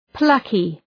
Προφορά
{‘plʌkı}
plucky.mp3